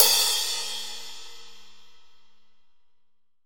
Index of /90_sSampleCDs/AKAI S6000 CD-ROM - Volume 3/Crash_Cymbal1/15-18_INCH_AMB_CRASH
15AMB CRS2-S.WAV